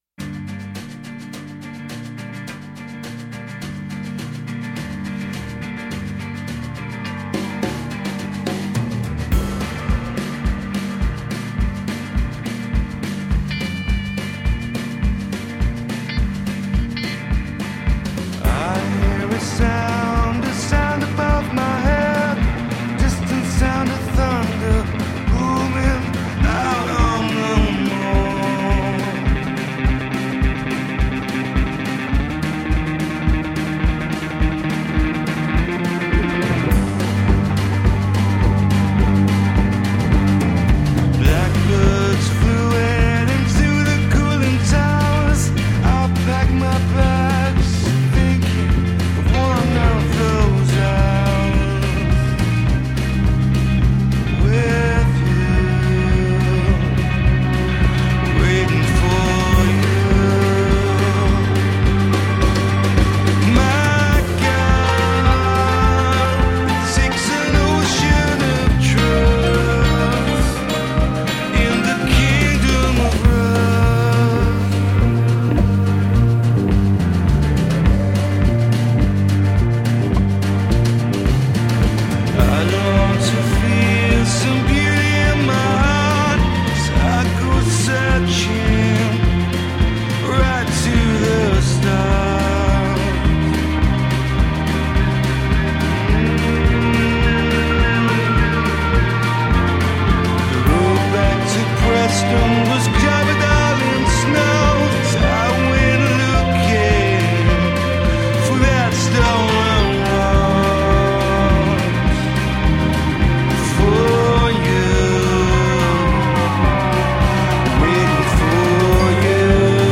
異趣電流強勢灌入搖滾軀殼
哀戚與溫暖，就像看見夕陽時那樣地同時並行著
感傷蕭瑟宛如北國鏢客